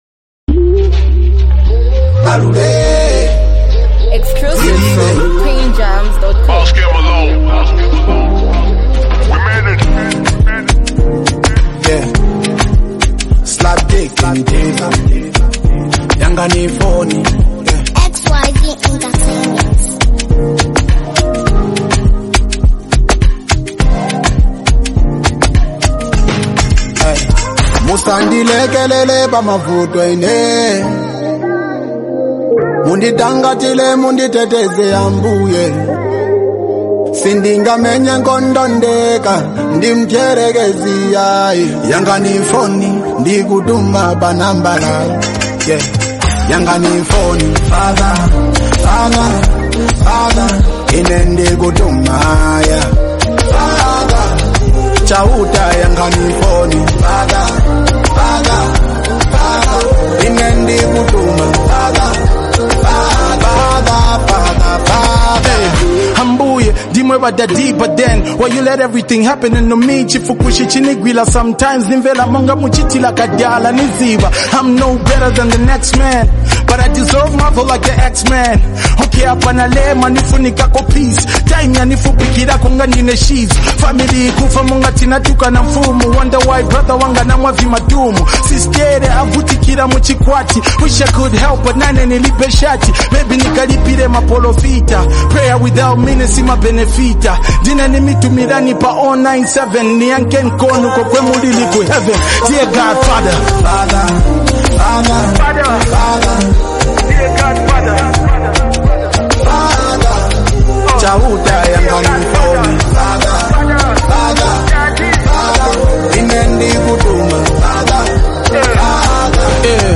deeply emotional and reflective song
adds a soulful and compassionate layer to the message
Through his smooth vocals